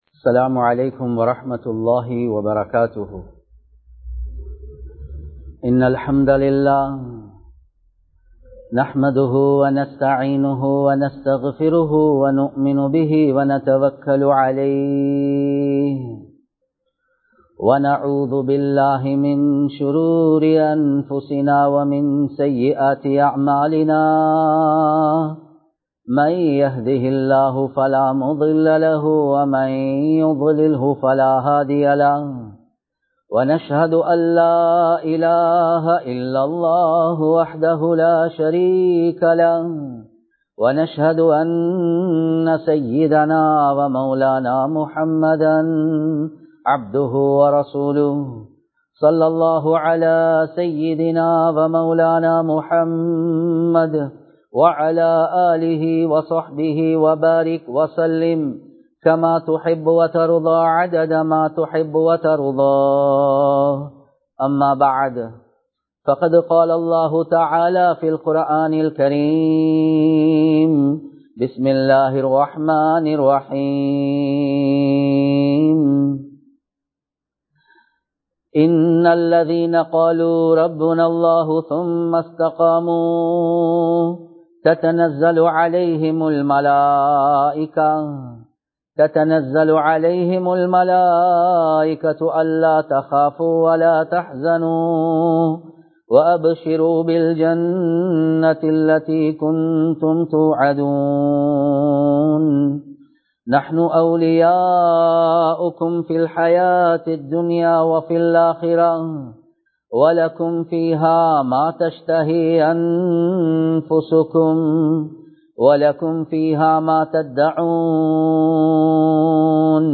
நிஃமத்துக்களை மறந்து விடாதீர்கள் | Audio Bayans | All Ceylon Muslim Youth Community | Addalaichenai
Kollupitty Jumua Masjith